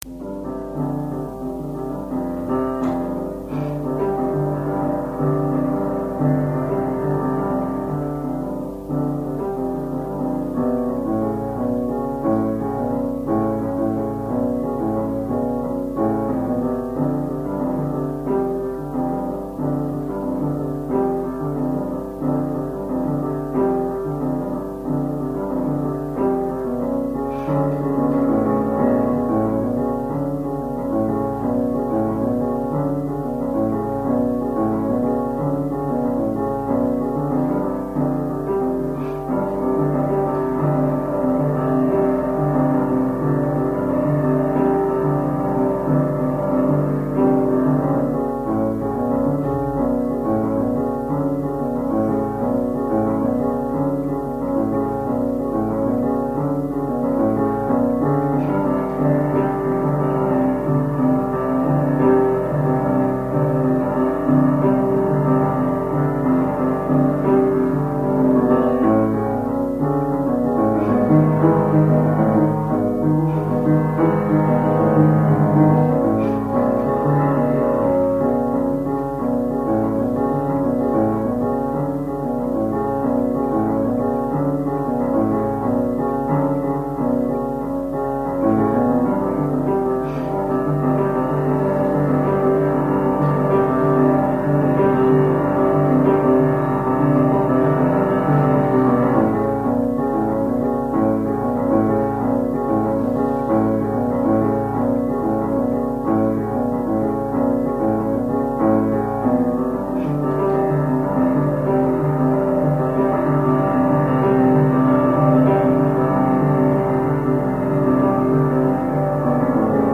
registratore a cassetta, nastri, giradischi, microfoni, pianoforte, minitastiera casio, canto